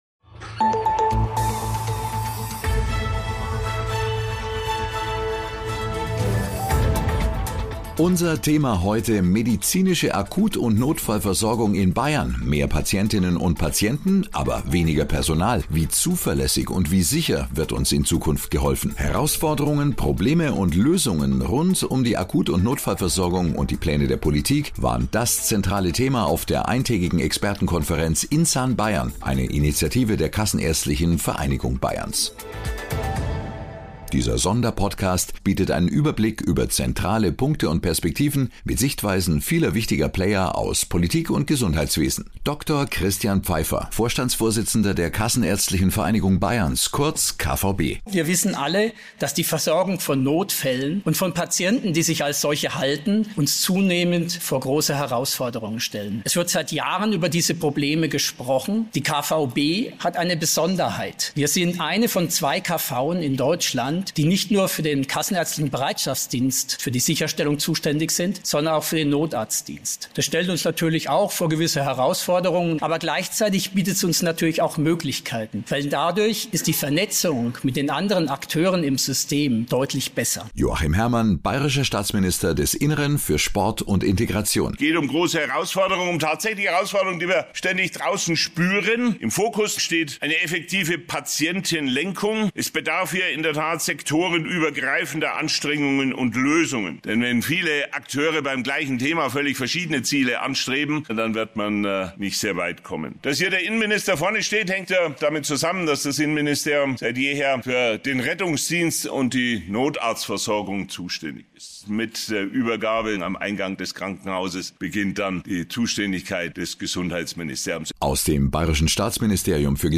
In unserer Sonderfolge berichtet Bayerns Innenminister Joachim Herrmann über die aktuelle Situation und die Pläne der Politik bei der Notfallversorgung in Bayern.